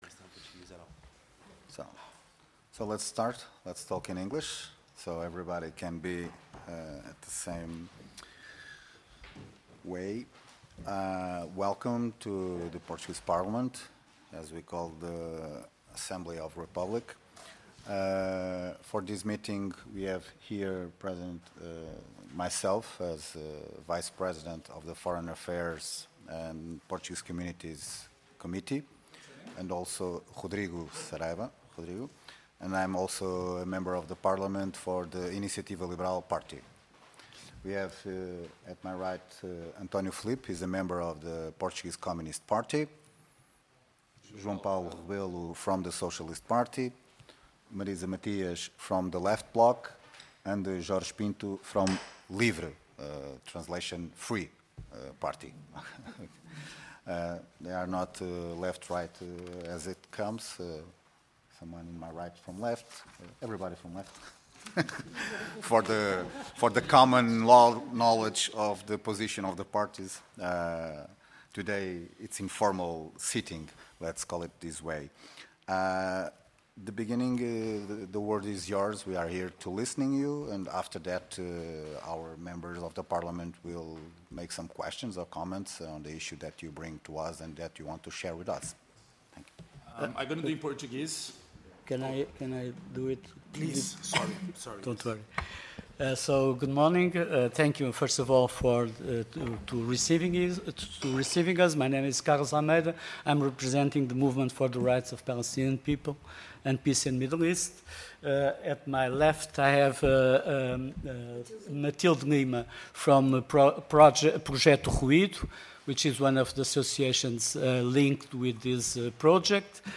Comissão de Negócios Estrangeiros e Comunidades Portuguesas Audiência Parlamentar